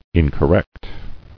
[in·cor·rect]